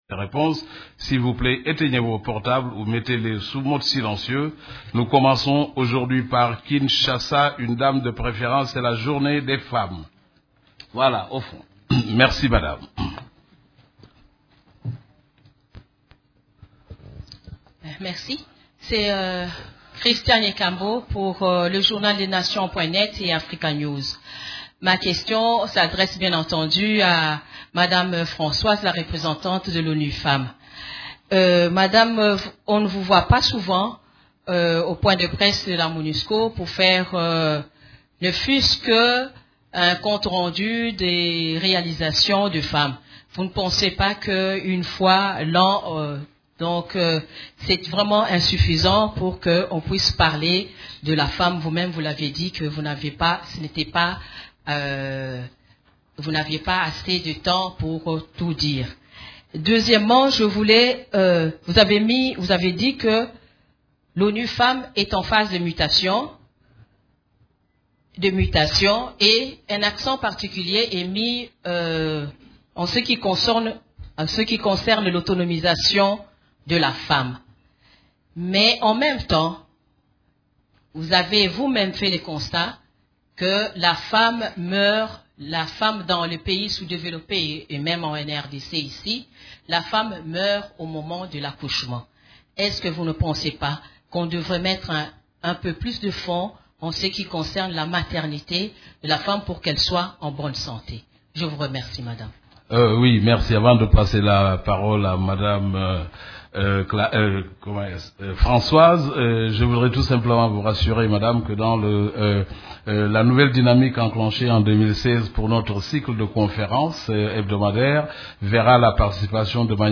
Conférence de presse du 9 mars 2016
La conférence de presse hebdomadaire des Nations unies du mercredi 9 mars à Kinshasa a porté sur les activités des composantes de la Monusco et la situation militaire en RDC.